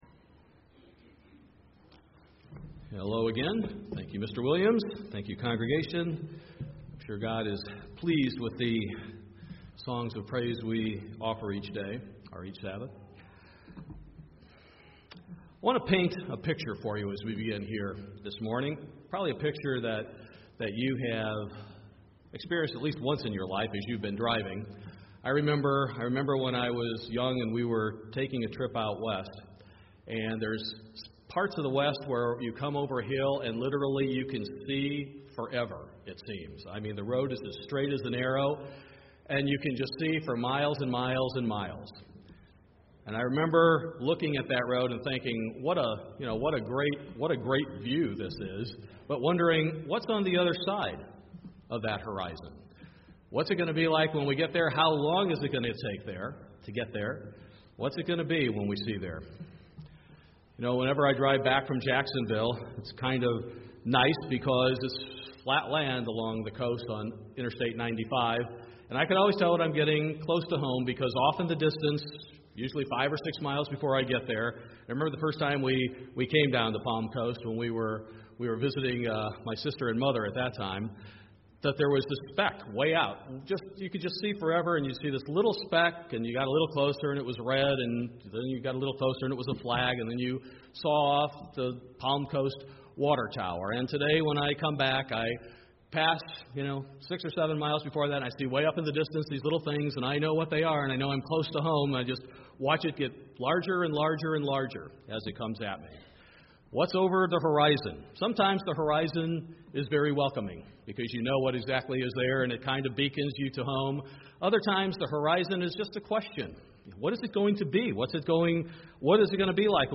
We need to make sure we are on the path that leads to the Kingdom of God UCG Sermon Transcript This transcript was generated by AI and may contain errors.